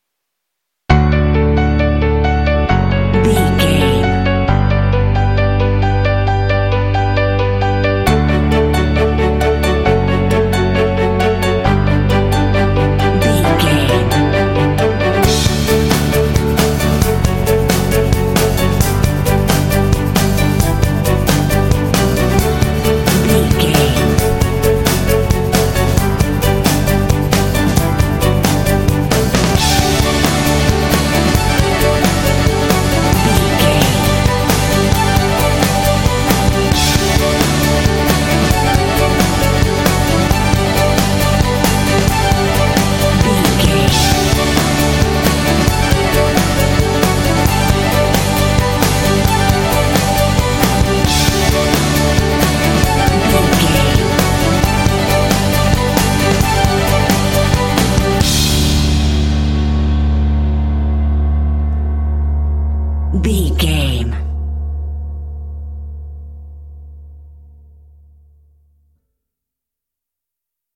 Bright and motivational music with a great uplifting spirit.
In-crescendo
Ionian/Major
epic
uplifting
powerful
strings
orchestra
percussion
piano
drums
rock
contemporary underscore
indie